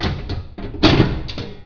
metalHeavyOnMetal_start.WAV